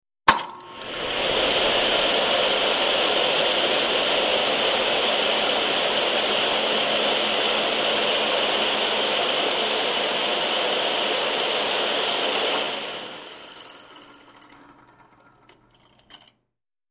Centrifuge; On / Off 1; Centrifuge; Click On / Spin / Click Off / Spin Down, Close Perspective.